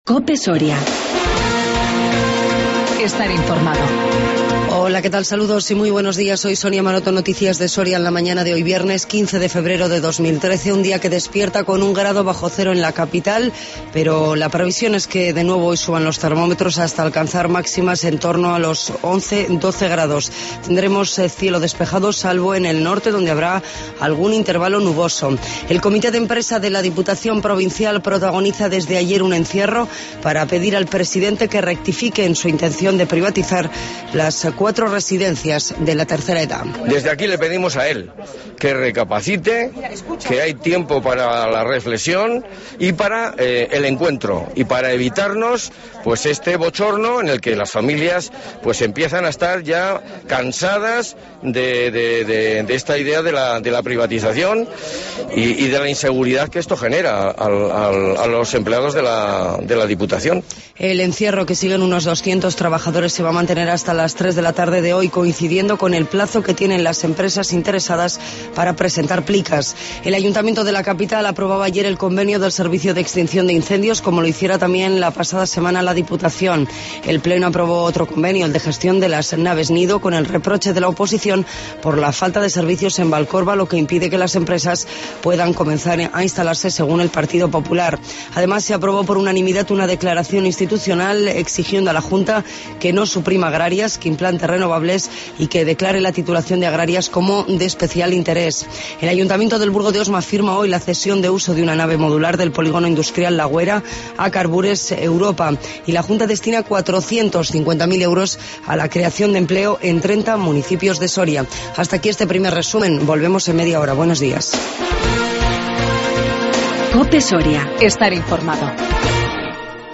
LA MAÑANA Informativo local